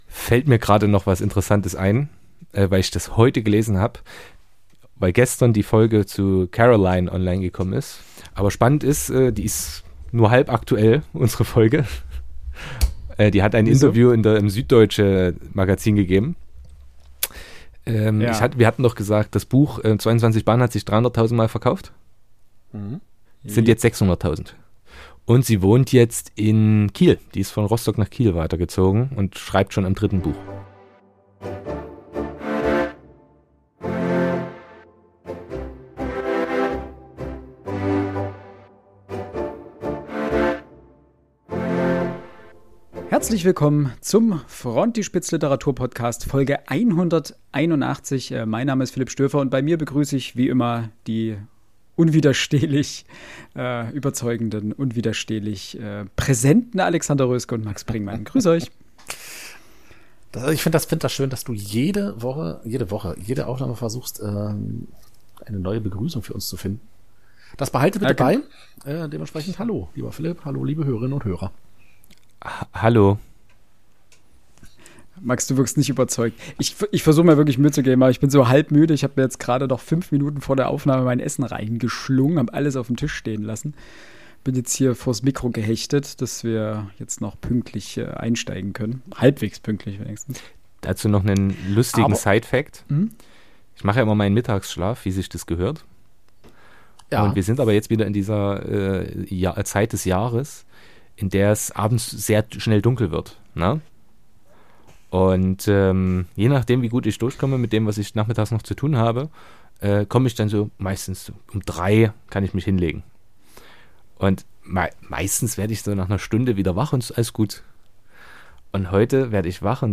Eine kleine Diskussion über die Art und Weise, wie wir Notizen verfassen und Texte niederschreiben. Ist digital immer besser und schneller?